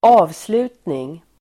Uttal: [²'a:vslu:tning]
avslutning.mp3